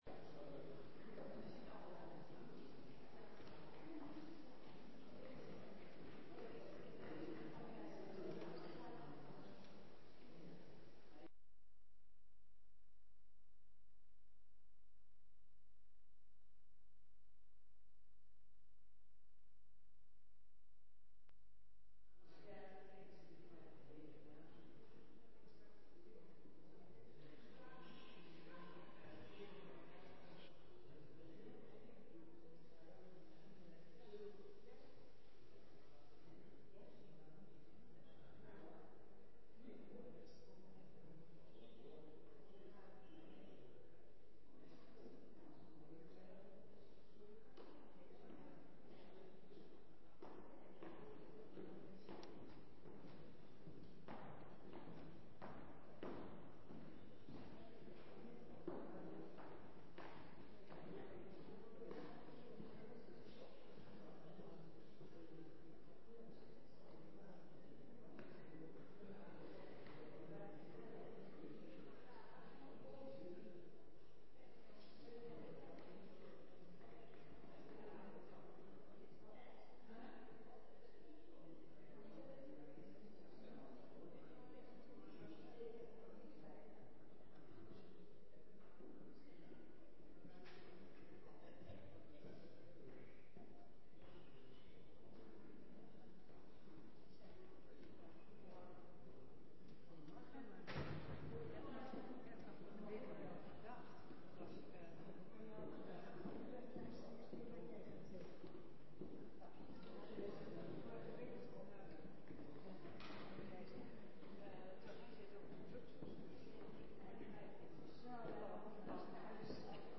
Tijdens een feestelijke overstapdienst hebben we naar aanleiding van het verhaal van David en Goliath stilgestaan bij de kleine dingen met een groots effect, zoals bijvoorbeeld ‘wensen’, en specifiek: […]